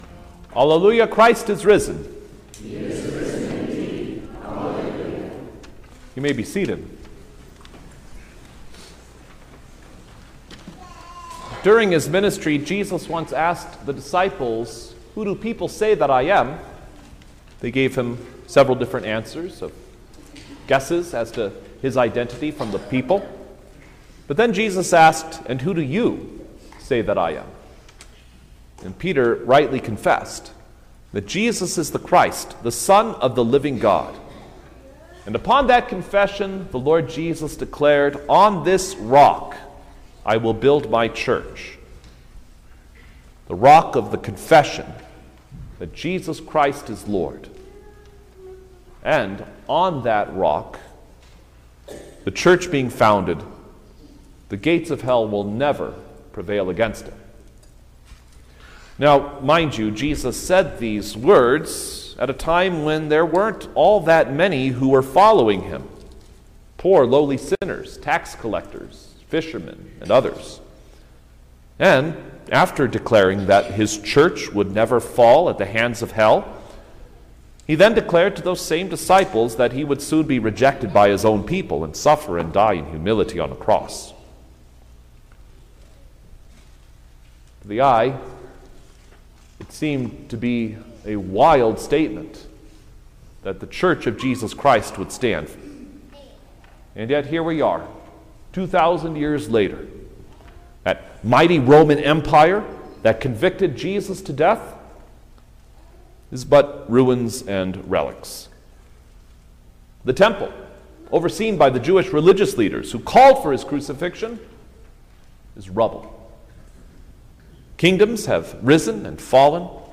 June-8_2025_The-Day-of-Pentecost_Sermon-Stereo.mp3